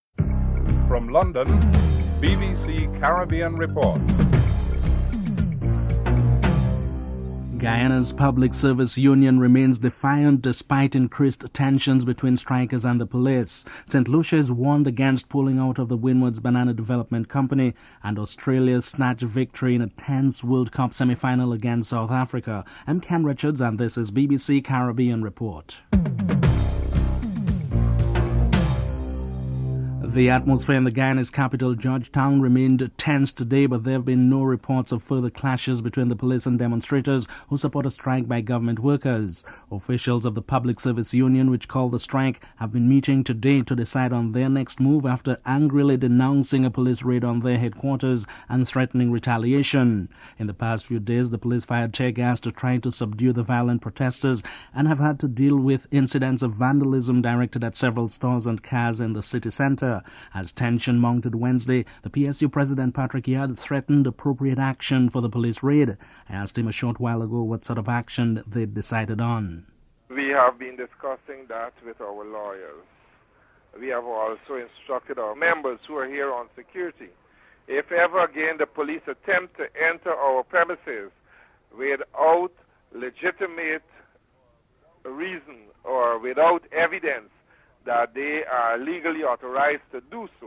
The British Broadcasting Corporation
7. In the second World Cup semi-final Australia won the game against South Africa. Colin Croft reviews the match (12: 09 – 15:25)